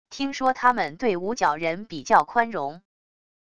听说他们对无角人比较宽容wav音频生成系统WAV Audio Player